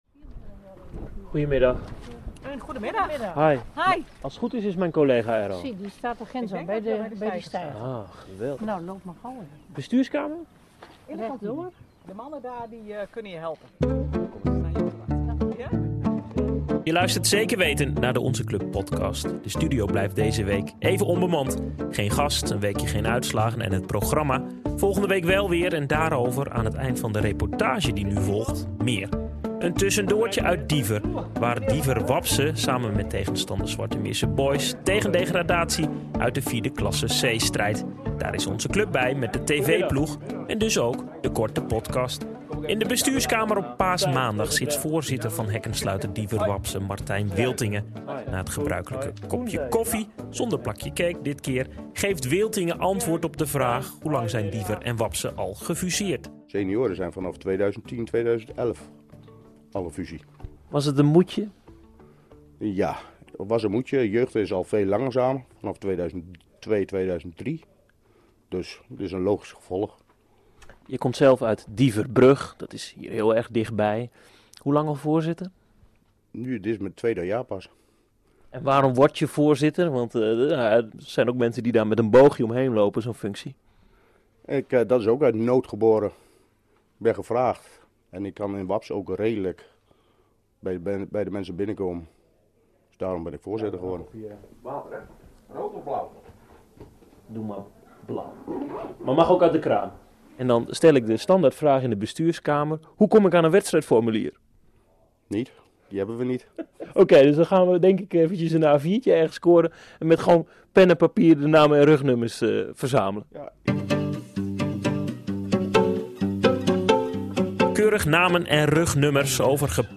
De studio van de Onze Club Podcast blijft vlak na Pasen even onbemand en daarom een 'audiosnack' vanuit Diever waar een felle strijd tegen degradatie naar de vijfde klasse wordt gestreden.